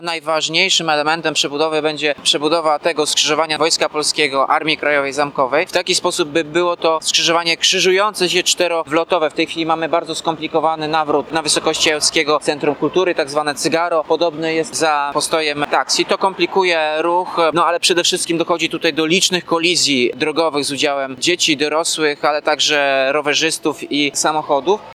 Mówi prezydent Andrukiewicz.